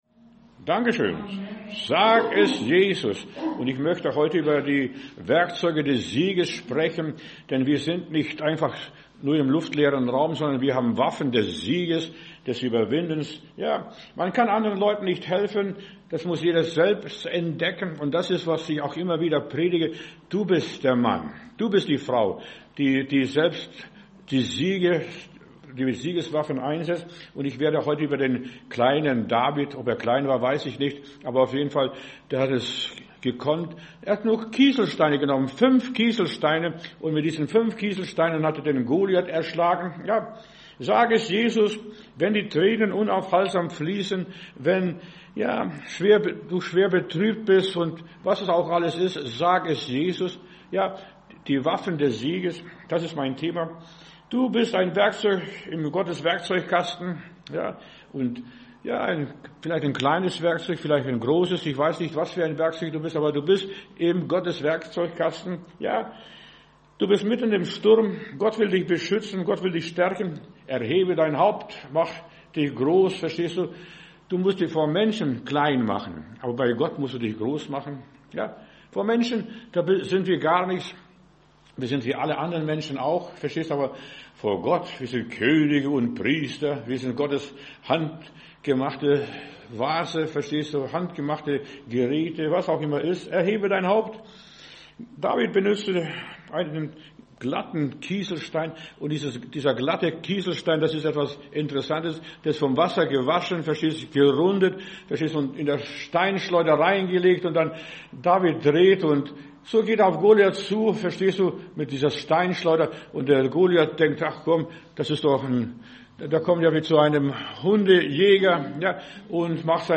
Werkzeuge des Sieges – Höre Gottes Wort